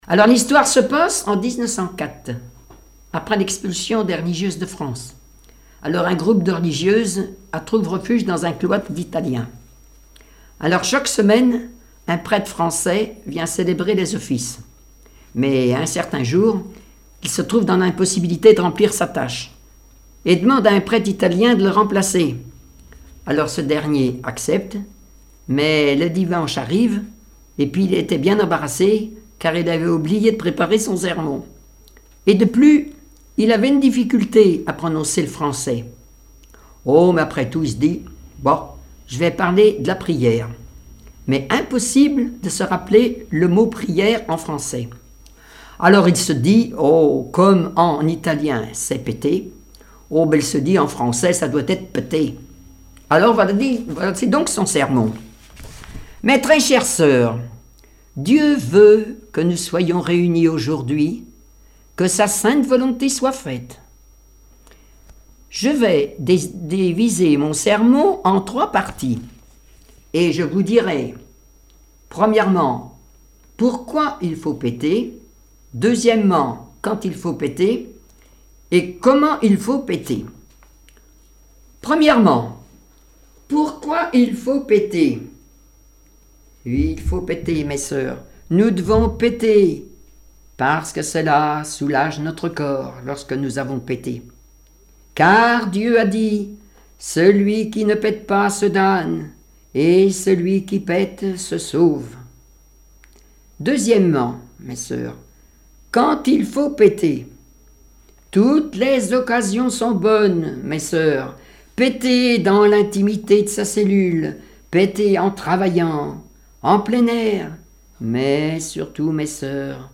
Genre sketch
Témoignages et chansons